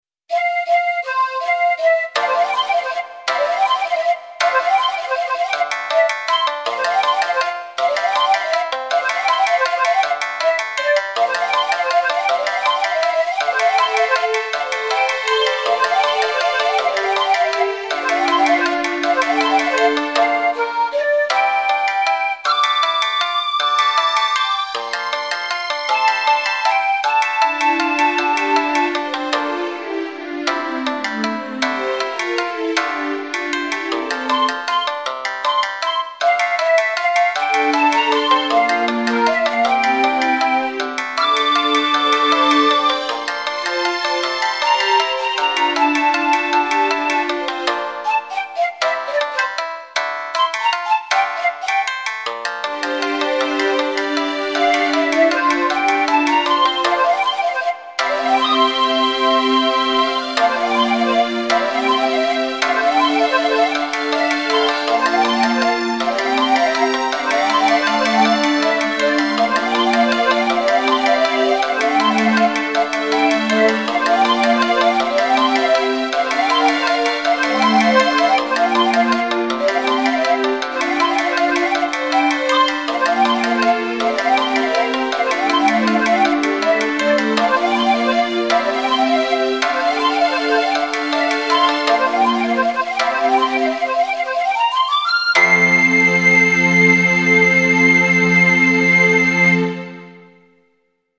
Japanese Music Style
ちょっと悲しげで勇ましい雰囲気。尺八、三味線、スローストリングスによる和風アレンジ。